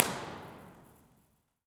Download this impulse response (right click and “save as”)
EchoThief Square Victoria Dome Battery Benson Purgatory Chasm Waterplace Park Tunnel to Heaven JFK Underpass JFK Underpass Concrete, asphalt.